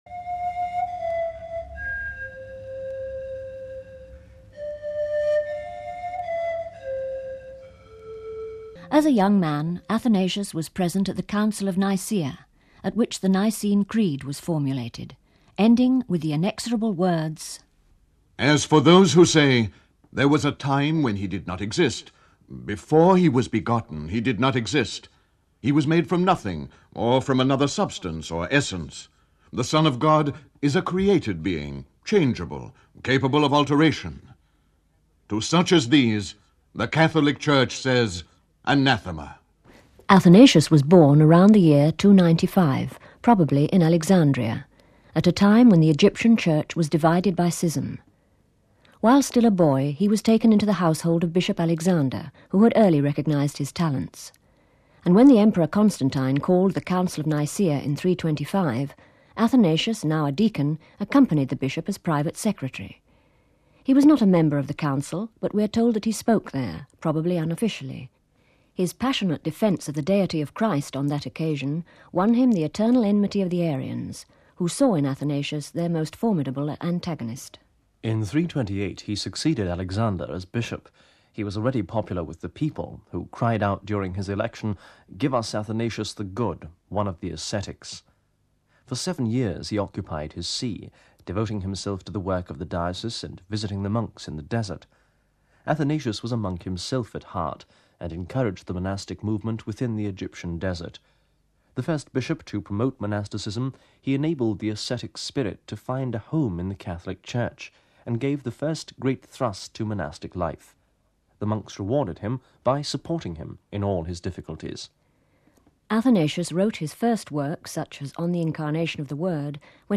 We bring you a sound portrait of Saint Athanasius, Doctor of the Church, Bishop of Alexandria for nearly half a century and champion o f Christian Orthodoxy against Arianism.